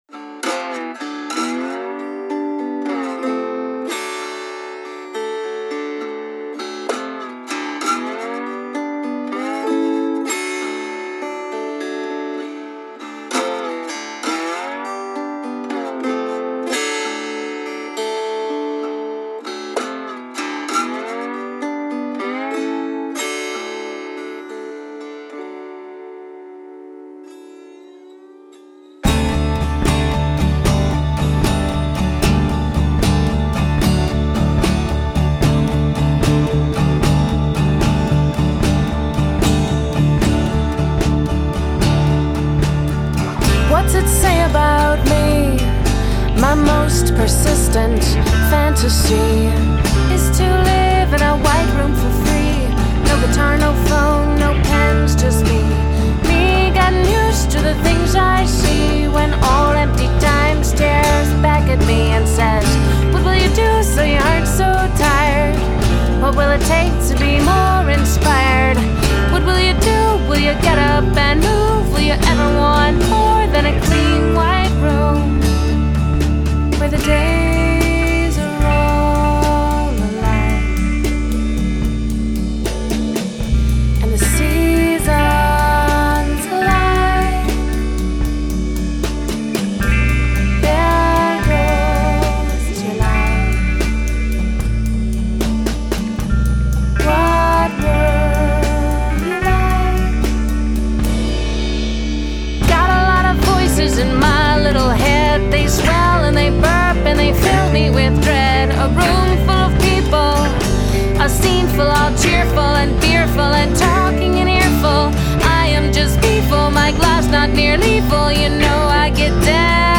Lyricist, guitarist, singer and poet